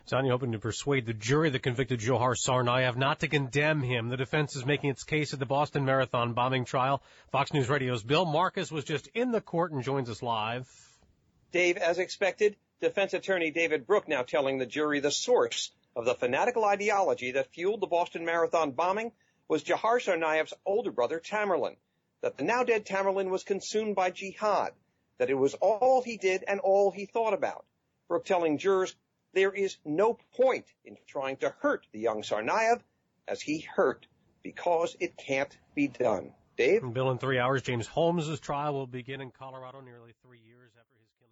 (BOSTON) APRIL 27 – FOX NEWS RADIO – 11AM LIVE –